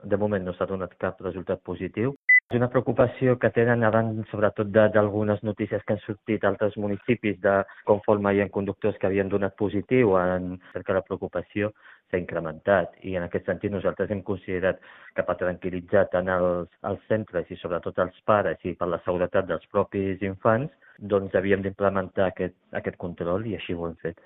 A principis de mes es va posar en marxa aquest pla de seguretat i, pel moment, no s’ha detectat cap positiu, tal com ha confirmat el tinent d’Alcaldia Soufian Laroussi en declaracions a Ràdio Calella TV, qui ha explicat que les notícies sobre els resultats de l’experiència prèvia a Pineda de Mar havien generat cert neguit entre les famílies.